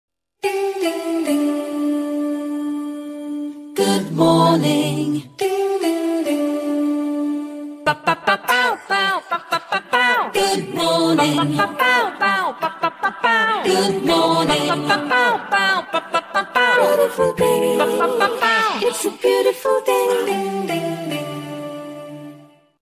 장르 웃긴